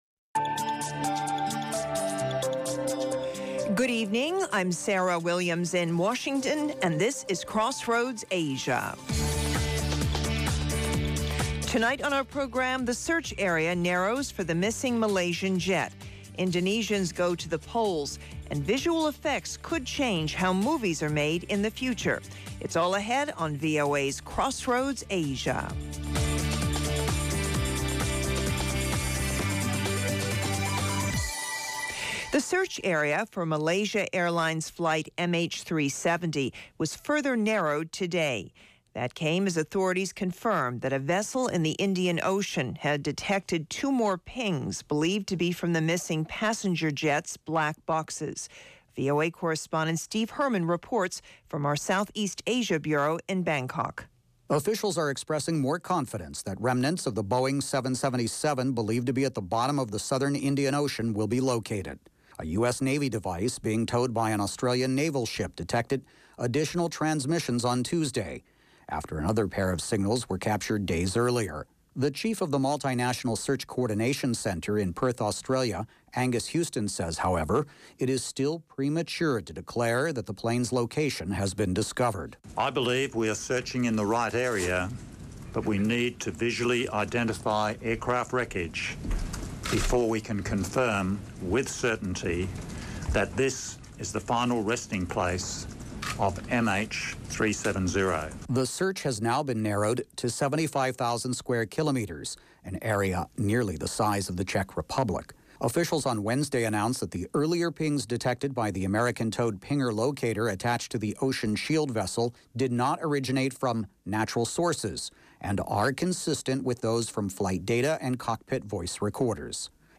Crossroads Asia offers unique stories and perspectives -- with in-depth interviews, and analysis.